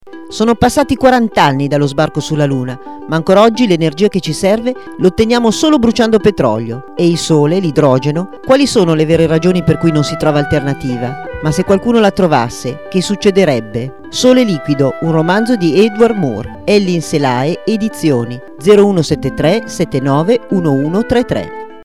spot  del libro che sta andando in onda sul circuito radiofonico POPOLARE Network